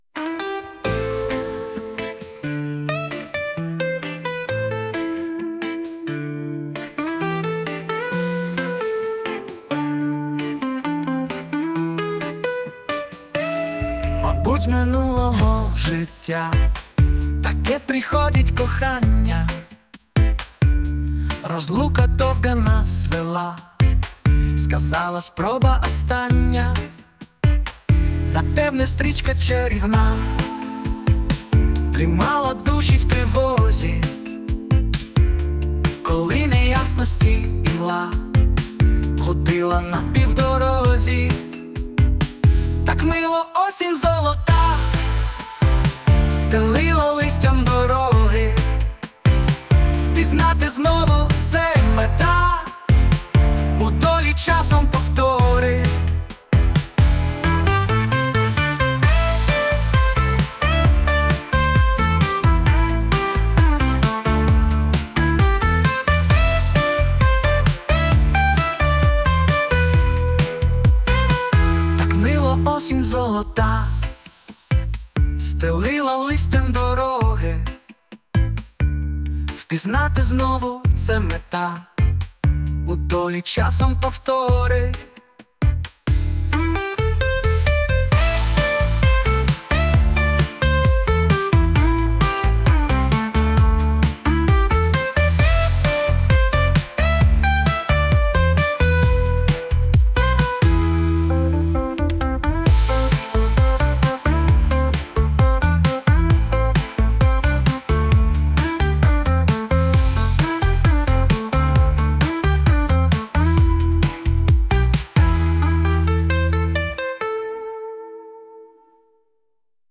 Музична композиція створена за допомогою SUNO AI
СТИЛЬОВІ ЖАНРИ: Ліричний
І пісня мелодійна, гарна получилась osen1 osen1 osen1 osen1 osen1